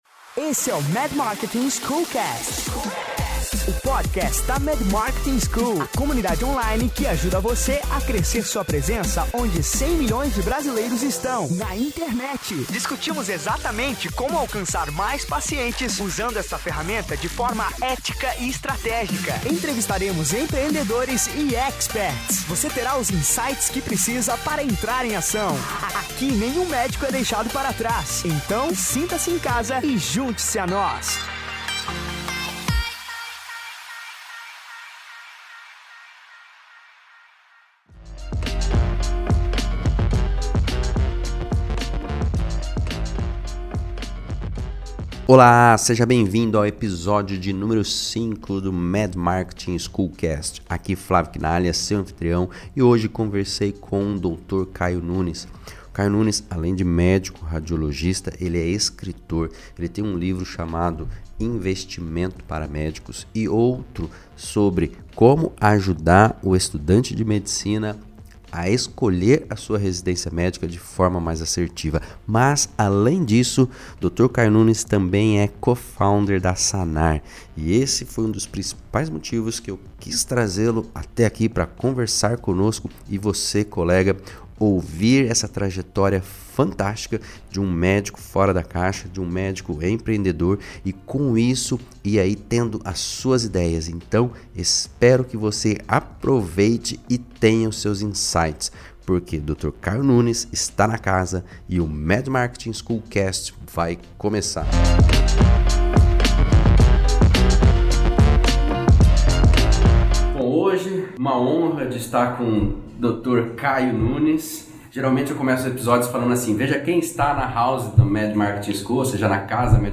No episódio dessa semana conversamos com o